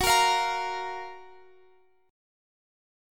Listen to F#M7 strummed